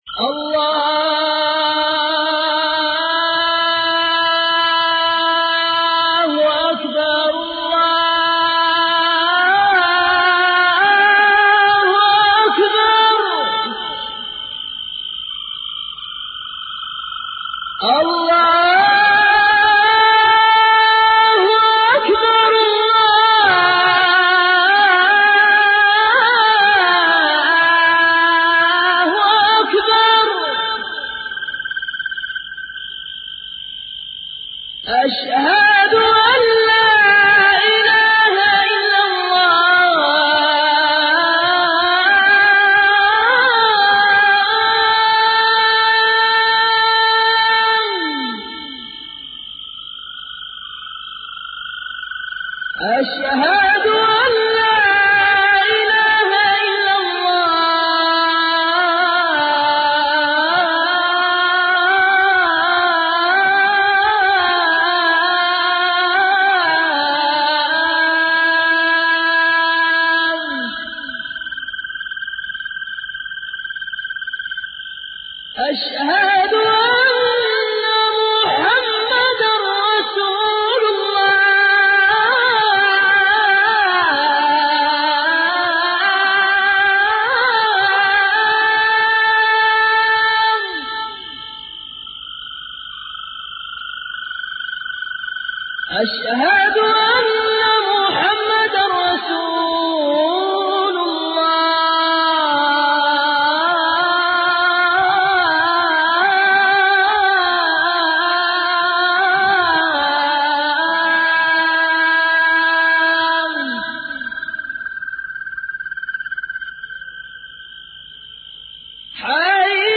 أناشيد ونغمات
عنوان المادة الأذان